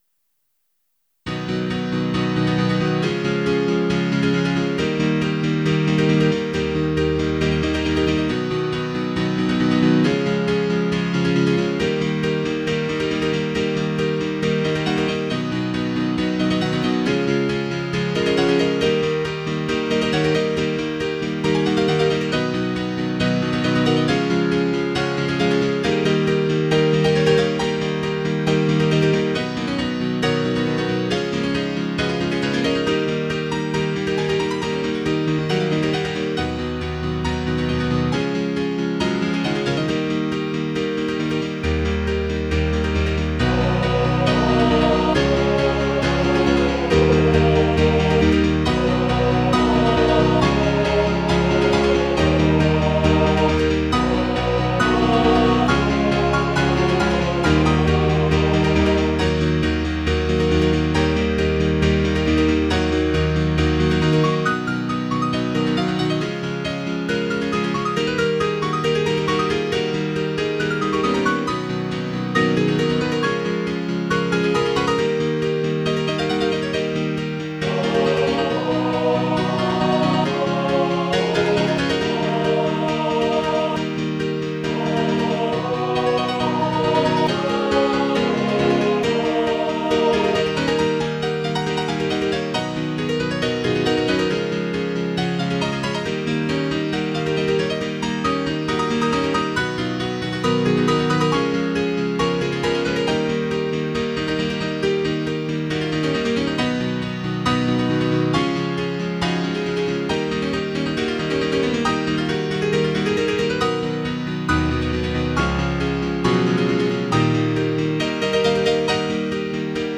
:-/ mp3 download wav download Files: wav mp3 Tags: Piano, Choral Plays: 1770 Likes: 0